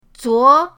zuo2.mp3